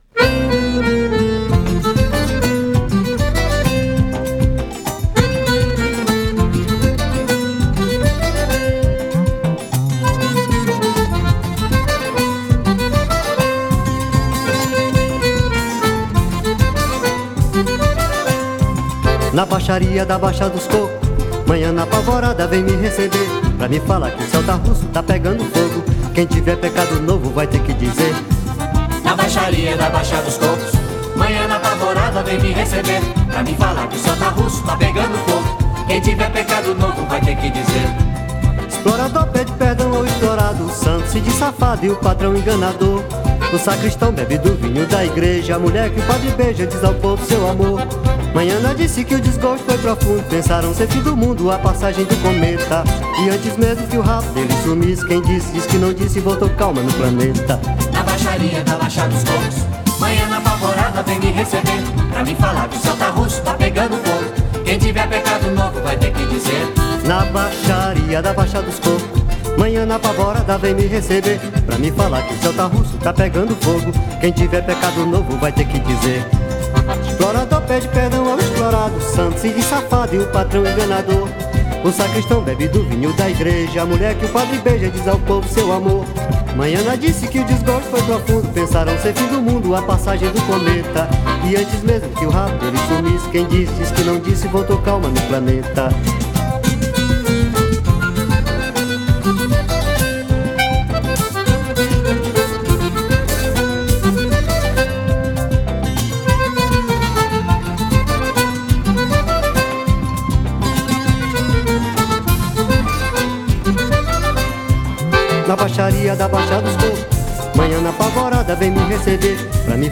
1618   03:59:00   Faixa:     Forró